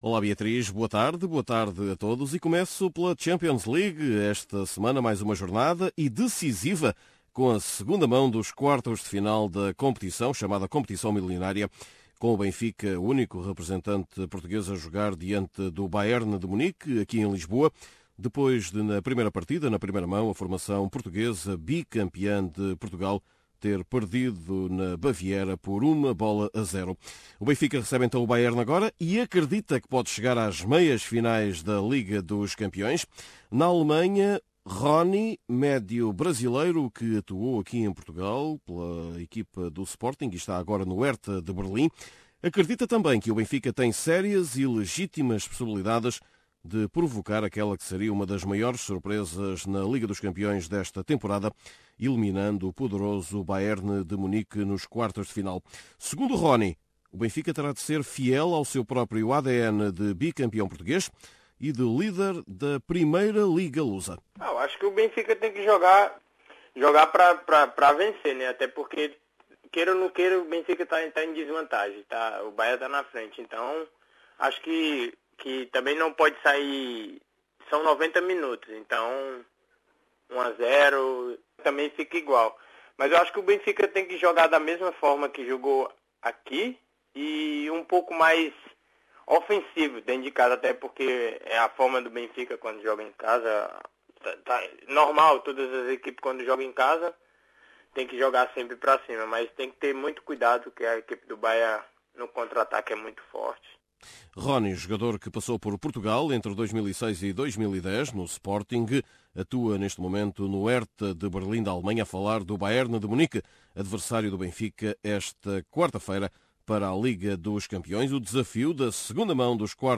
Neste boletim semanal, vamos conhecer ainda a crise do FC Porto, bem como o onze ideal da seleção lusa antes do Euro-2016.